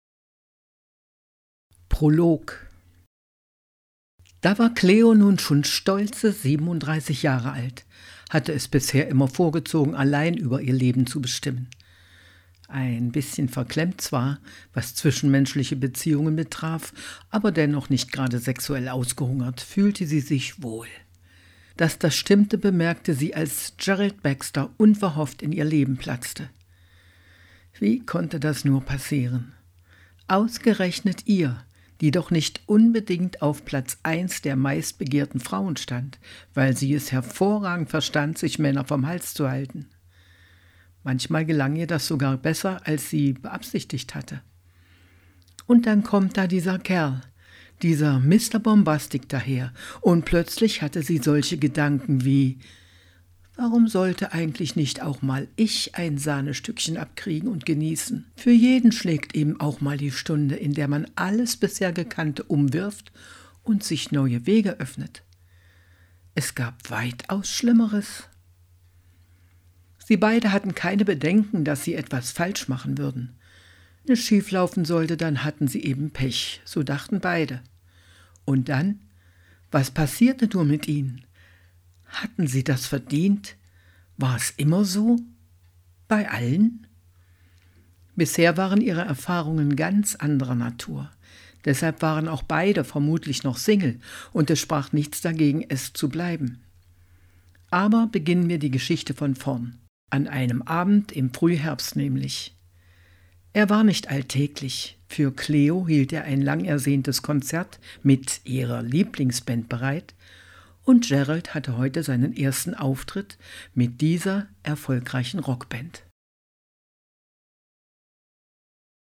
Willkommen in der Hörbuch Welt!
Man spürt die Spannung, die Verletzlichkeit und den Sog dieser Beziehung in jeder Szene. Ihre Stimme trifft die Mischung aus Härte, Ironie, Zärtlichkeit und dem unvermeidlichen Chaos dieser Liebe sehr gut.